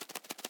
minecraft / sounds / mob / parrot / fly4.ogg
fly4.ogg